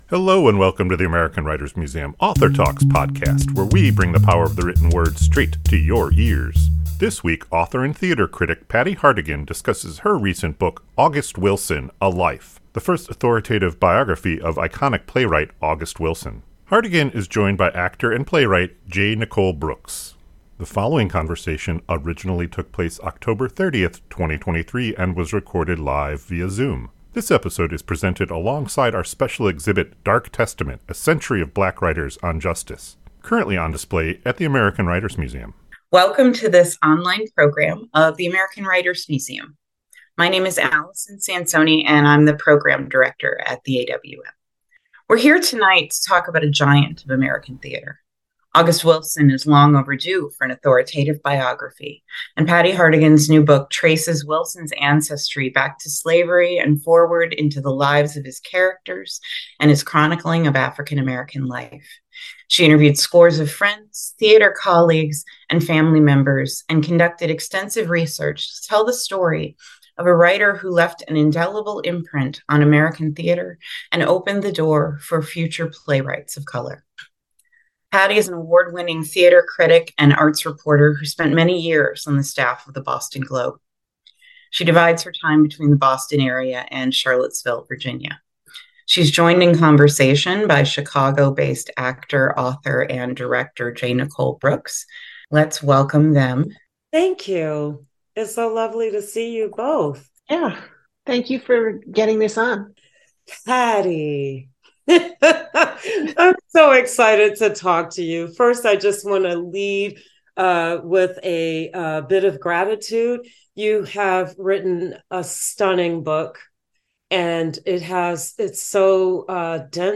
This conversation originally took place October 30, 2023 and was recorded live via Zoom.